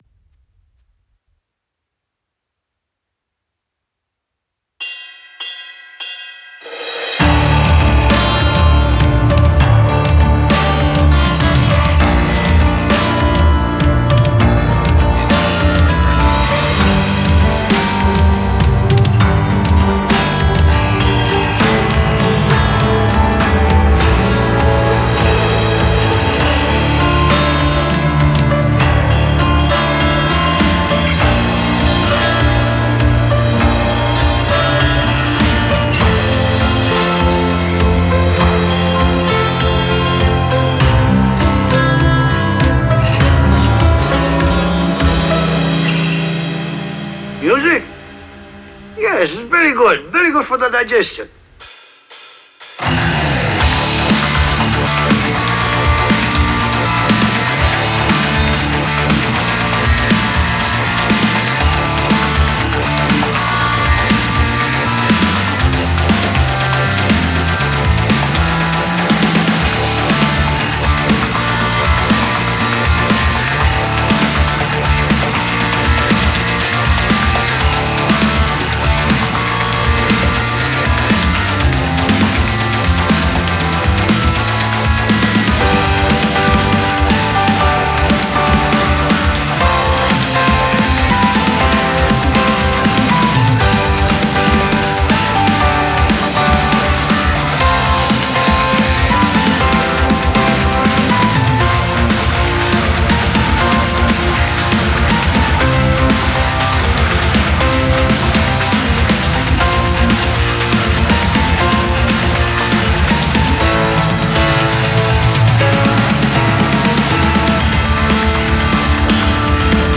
Instrumental Metal Track Feedback
This one has ghost notes and dynamics. But it has to be heard also, so appropriate treatment like compression EQing and saturation was necessary for it to cut through.
The track has also Lead guitars that I am mixing right now.